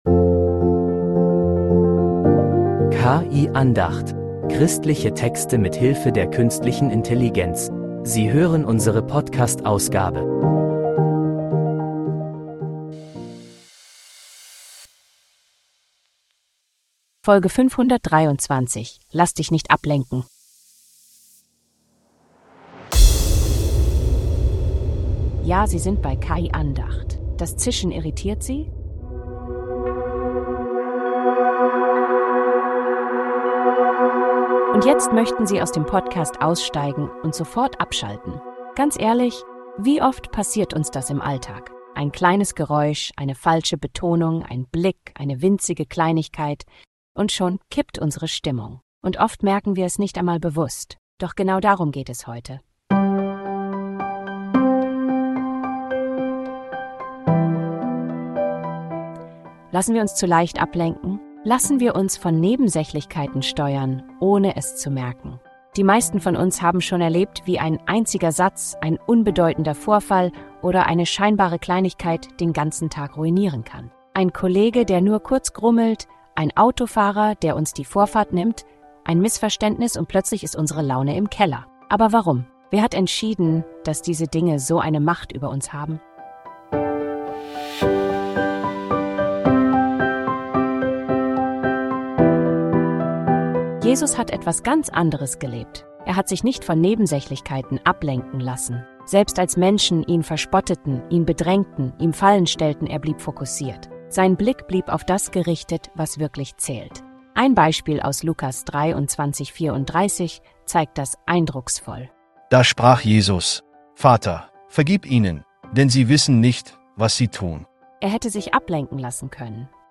Ein Zischen, dann ein Heulton – unangenehm?